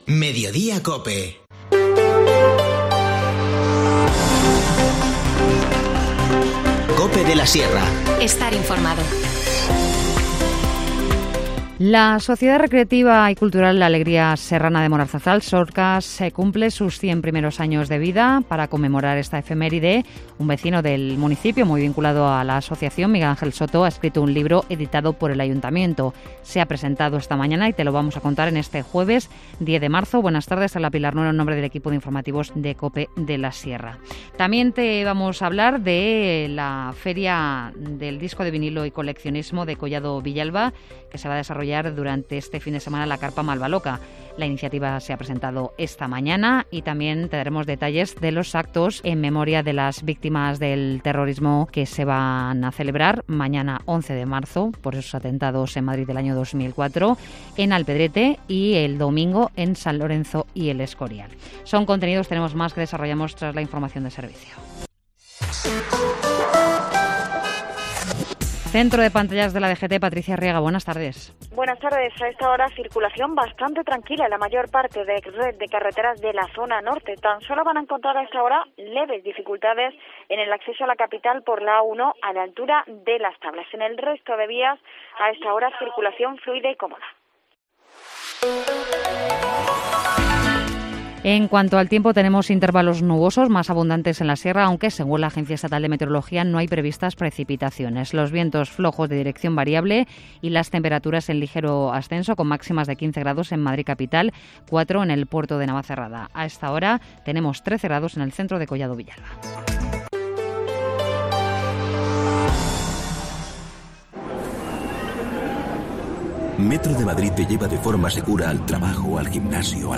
Informativo Mediodía 10 marzo